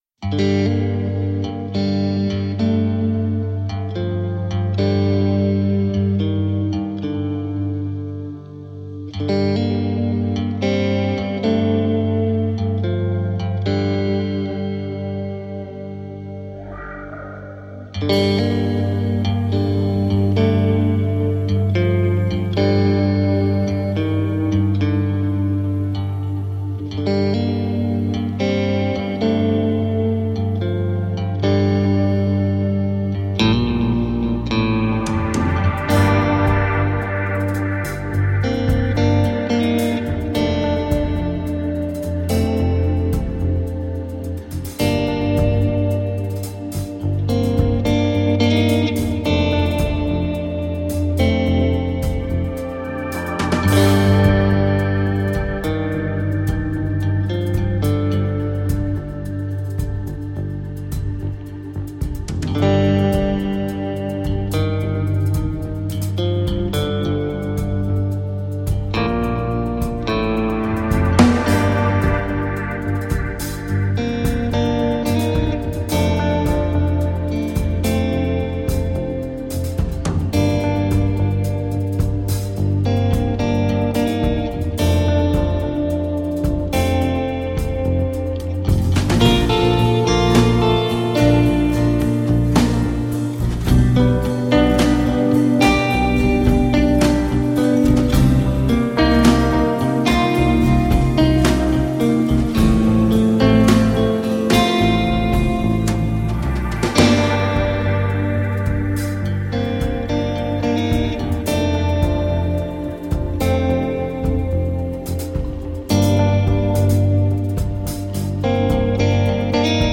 Evocative, melodic and haunting instrumental guitar music.